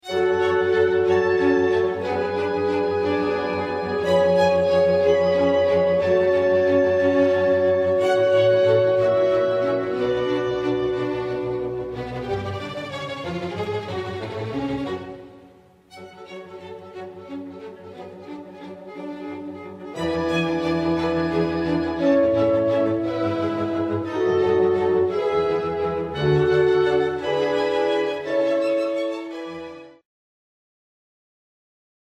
Música del Clásico I